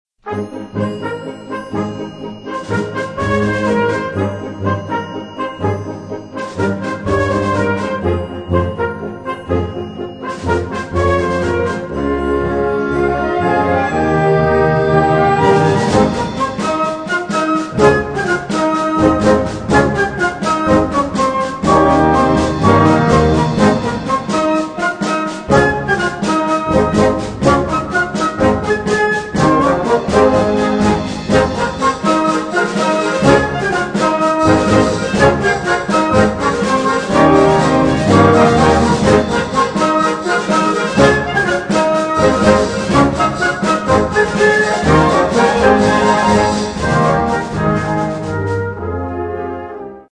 Catégorie Harmonie/Fanfare/Brass-band